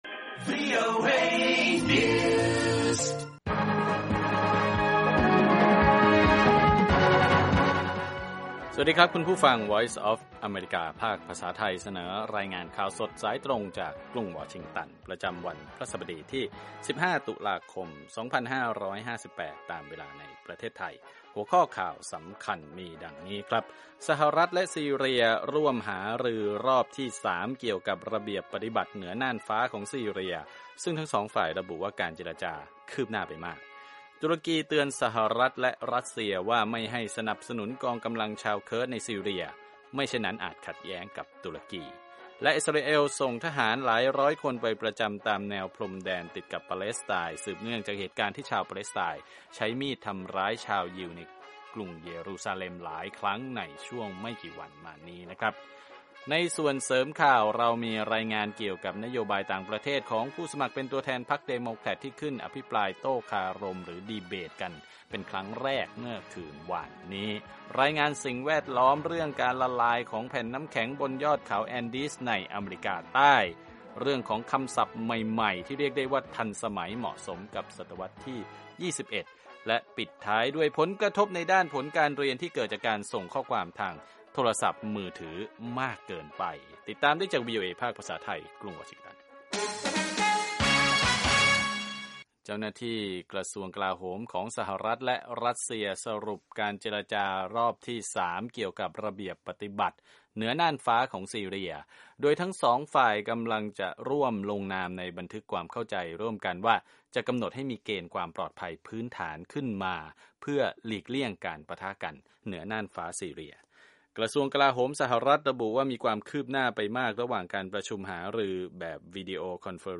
ข่าวสดสายตรงจากวีโอเอ ภาคภาษาไทย 8:30–9:00 น. วันพฤหัสบดีที่ 15 ต.ค 2558